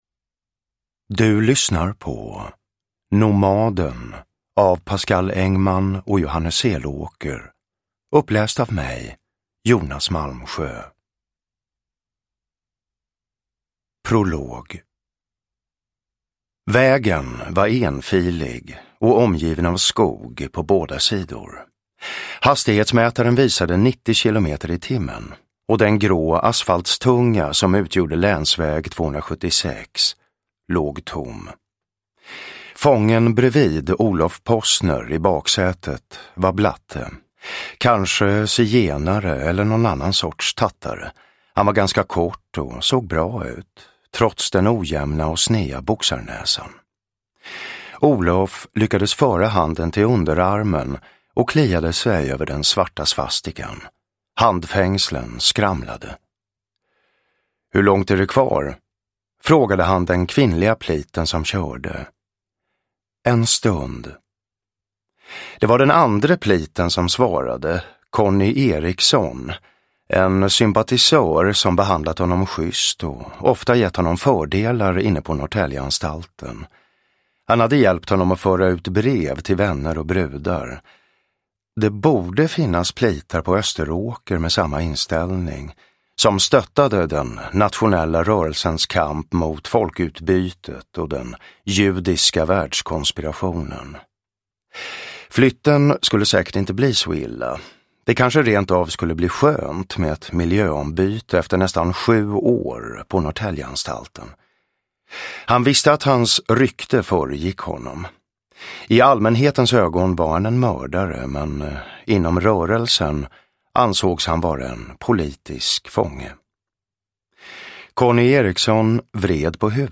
Downloadable Audiobook
Ljudbok
Jonas Malmsjö